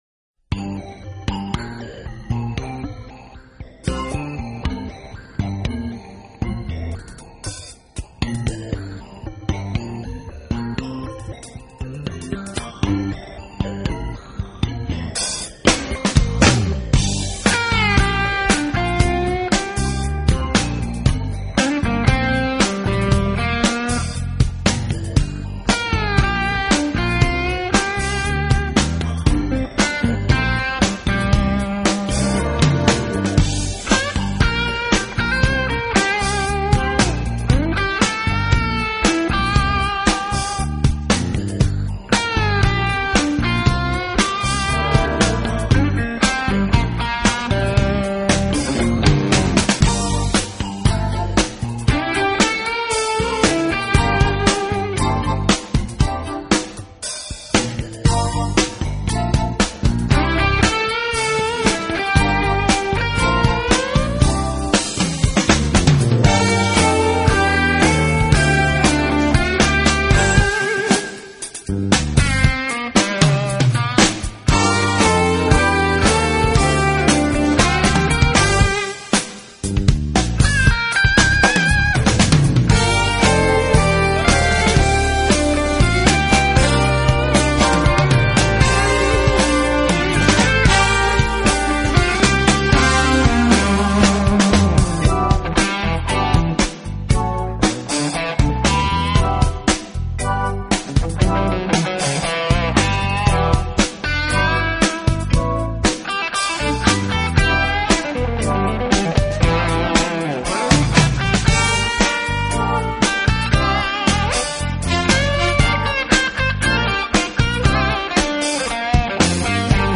爵士吉他